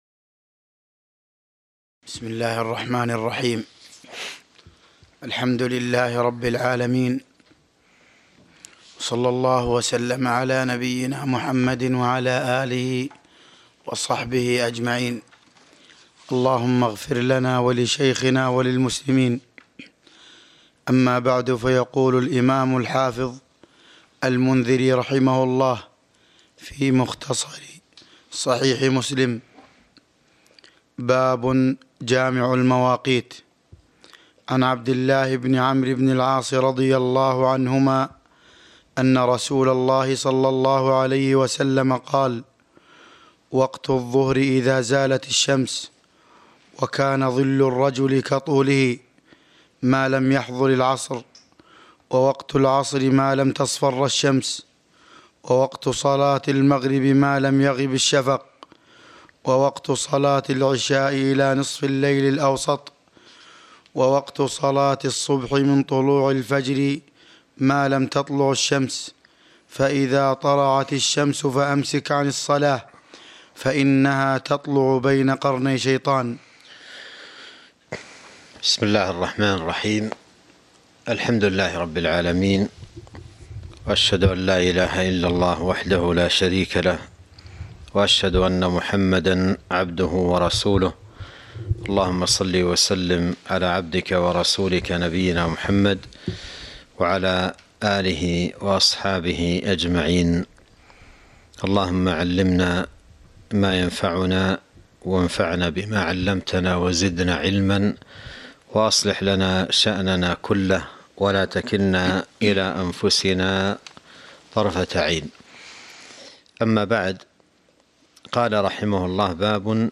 تاريخ النشر ١٥ ربيع الثاني ١٤٤٢ هـ المكان: المسجد النبوي الشيخ